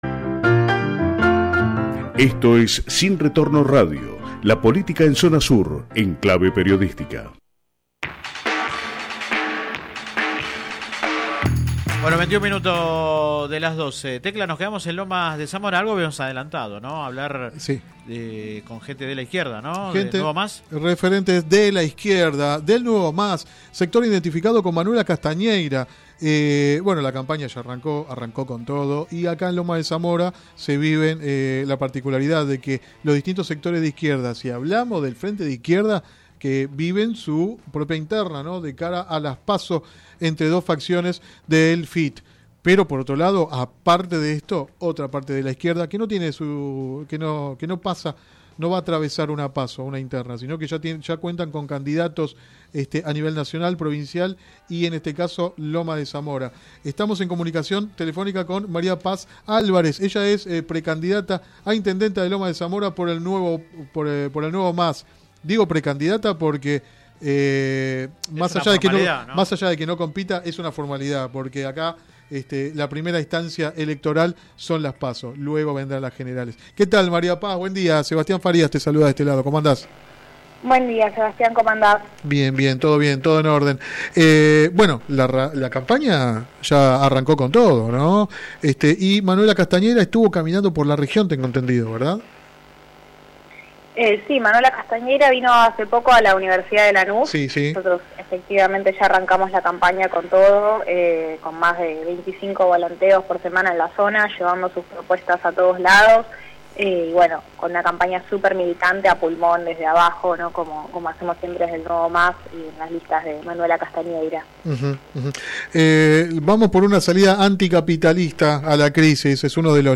Click acá entrevista radial https